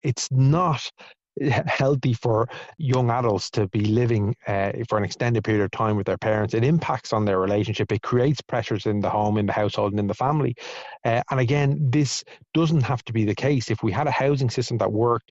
Labour’s Duncan Smith says it’s a sign of a broken housing sector in this country………….